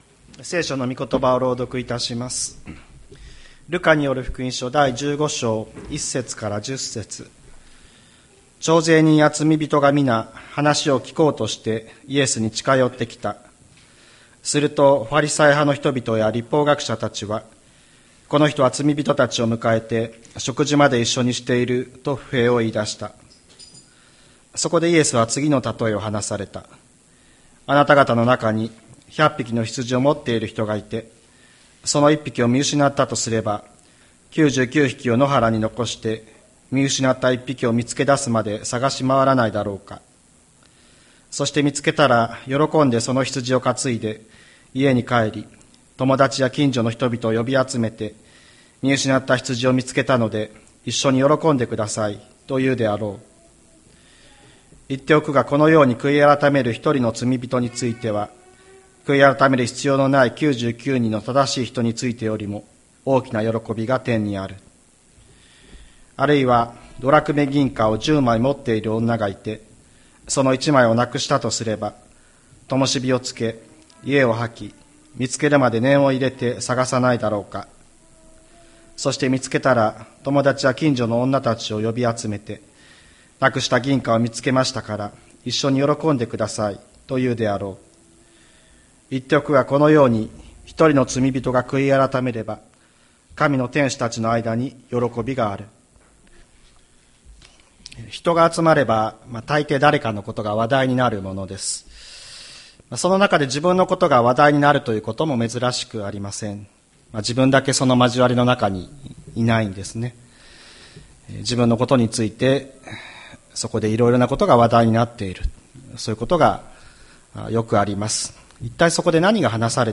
千里山教会 2023年10月01日の礼拝メッセージ。